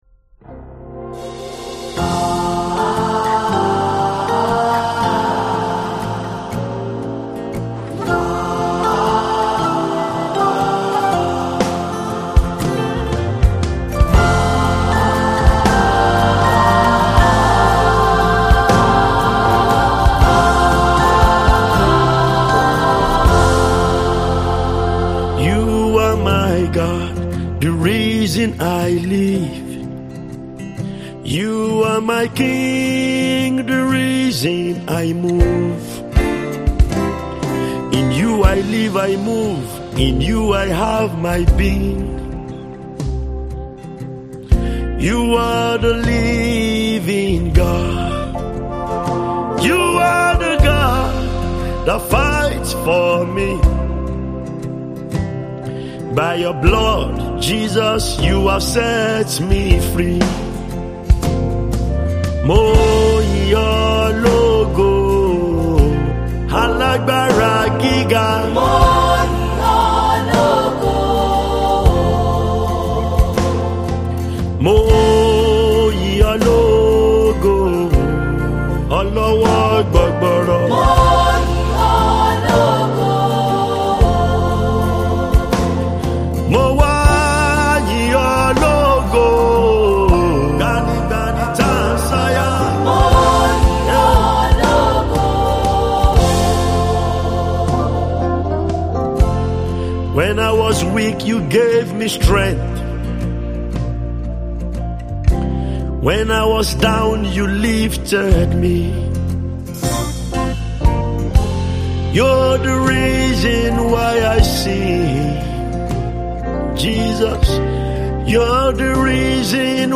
Nigerian gospel music minister and songwriter
a psalm of adoration